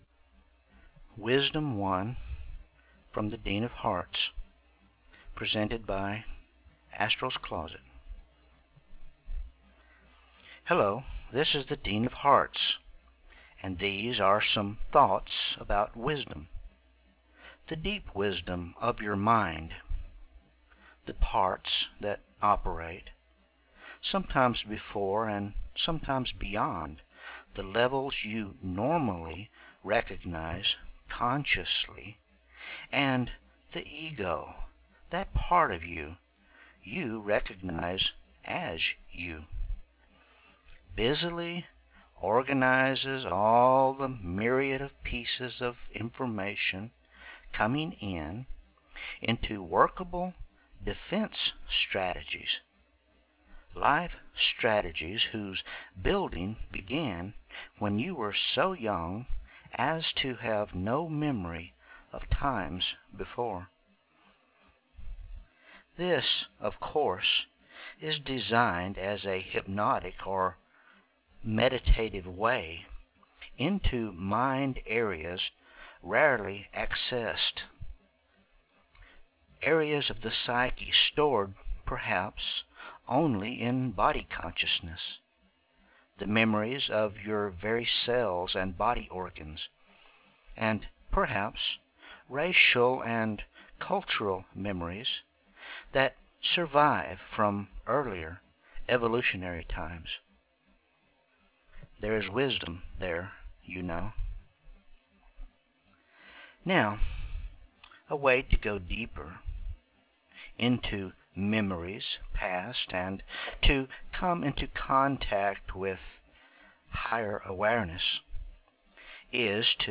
Free Hypnosis Download